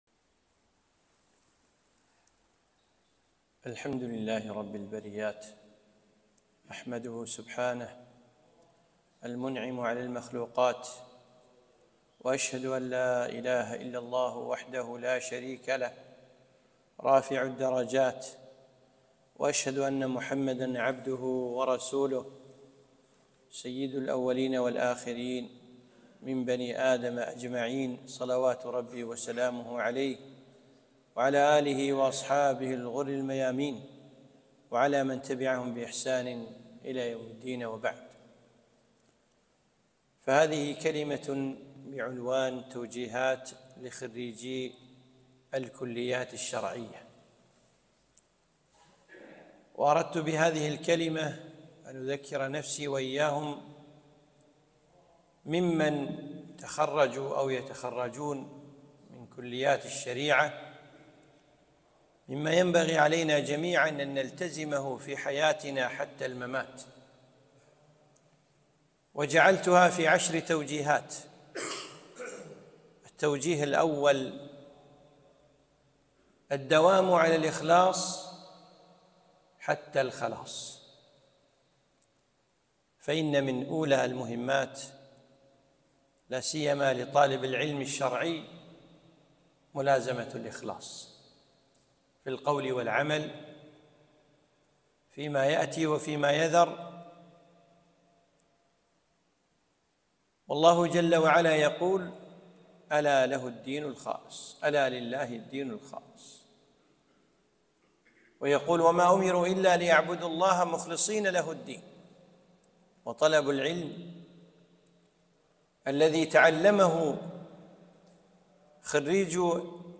محاضرة - توجيهات لخريجي كلية الشريعة